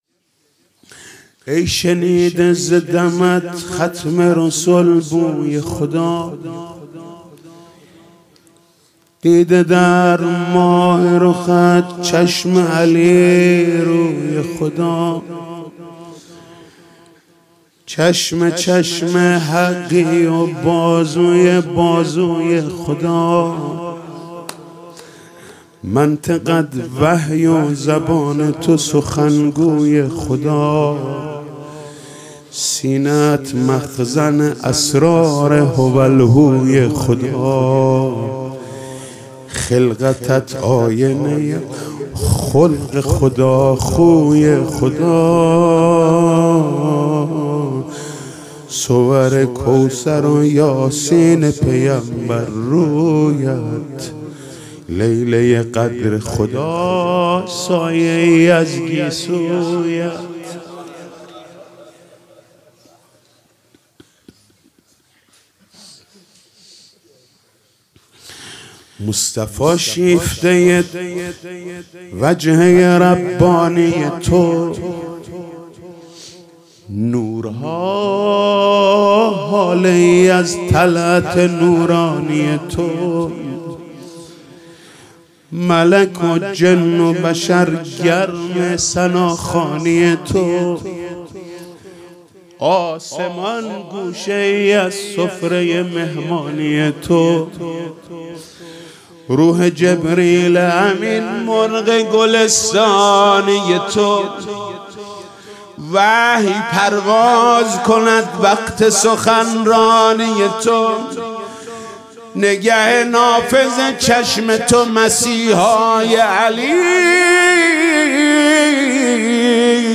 فاطمیه 95 - روضه - ای شنیده ز دمت ختم رسل بوی خدا